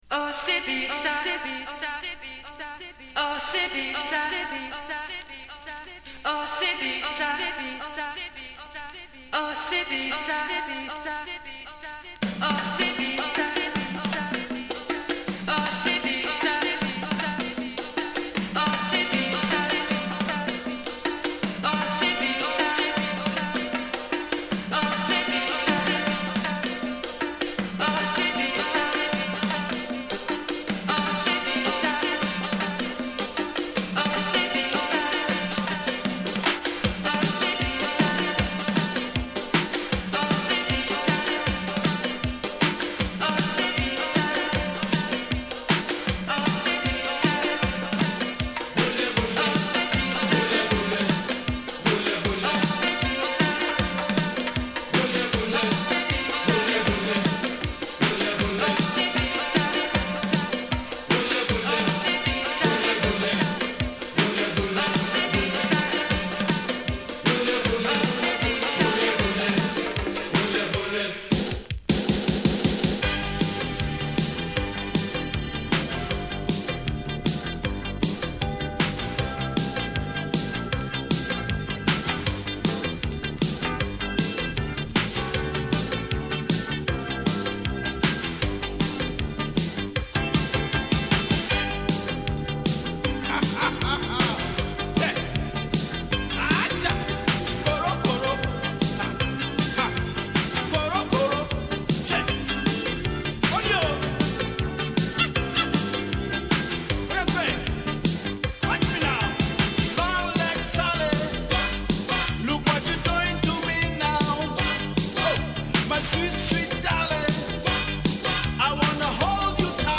レゲエやファンクの要素を取入れたガーナ出身のアフロロック/ファンク・バンド。